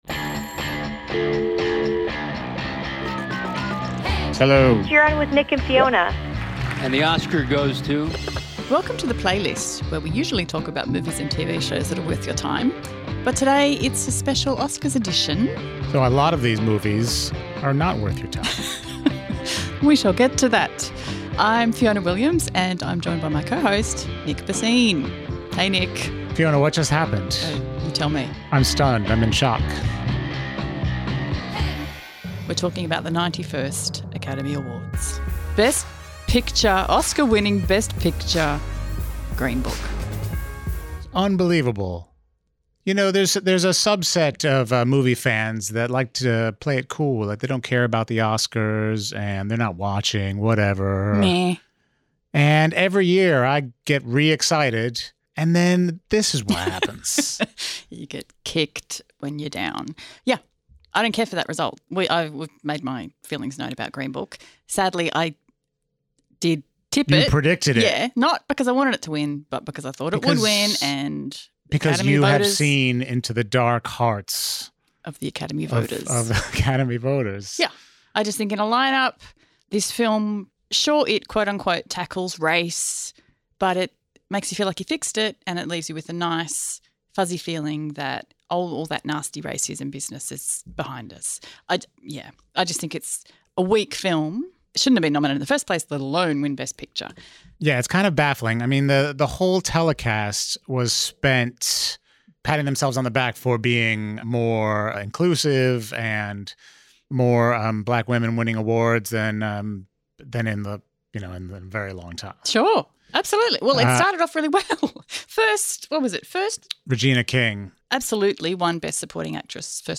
Recorded just minutes after the end of the broadcast of the 91st Academy Awards.